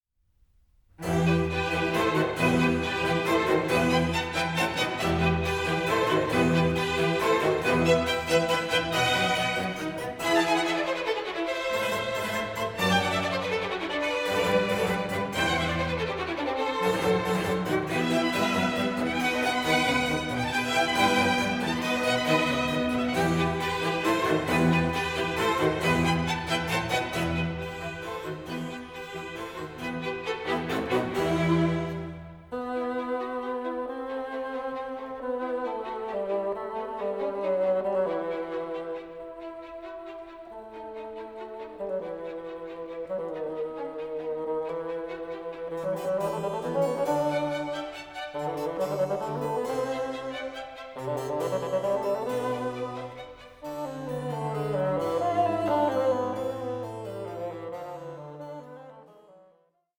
Bassoon Concerto in E minor